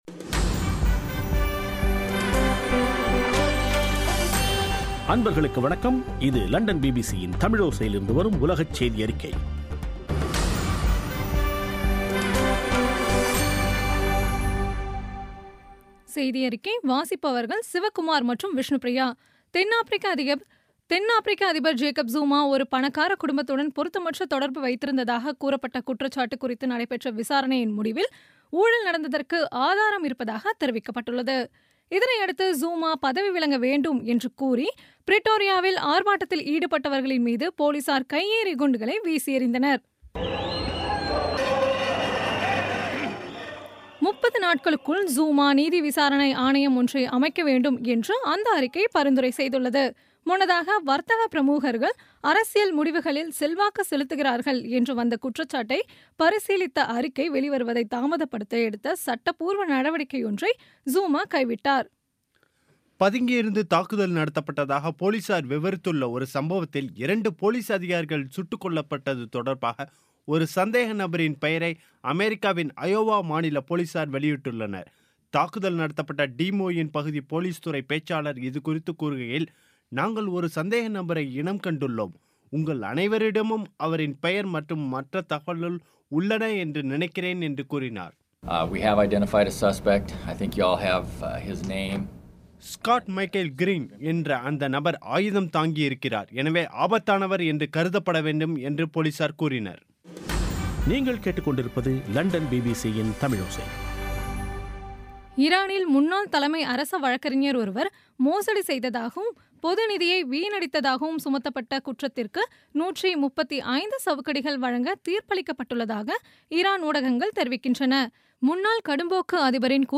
இன்றைய (நவம்பர்2ம் தேதி ) பிபிசி தமிழோசை செய்தியறிக்கை